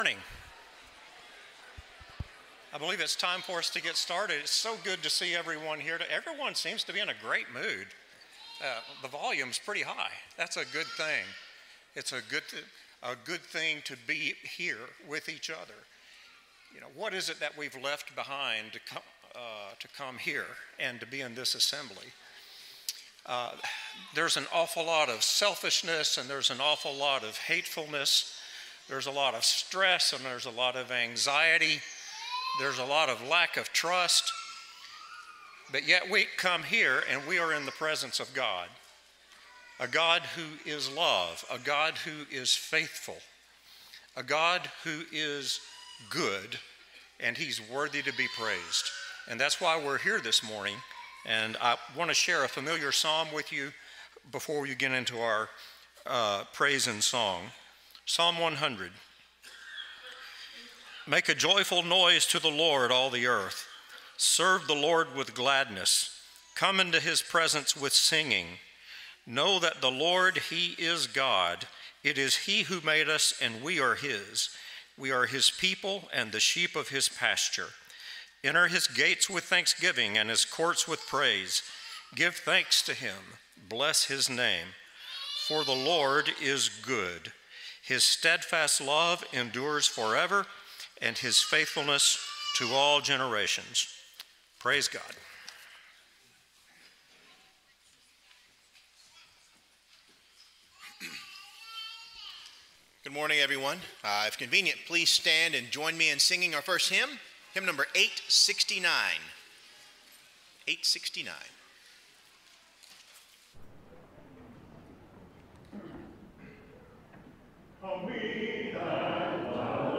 Ephesians 4:3, English Standard Version Series: Sunday AM Service